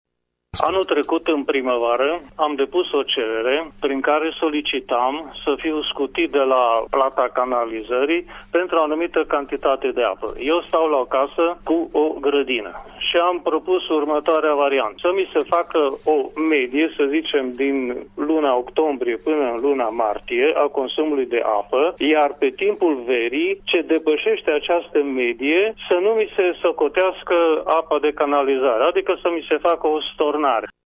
Mai mulţi ascultători Radio Tîrgu-Mureş au sesizat în emisiunea “Părerea Ta” de azi faptul că autorităţile ar trebui să taxeze altfel apa pentru udarea grădinilor , adică să existe contoare separate: